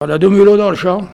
Enquête Arexcpo en Vendée
locutions vernaculaires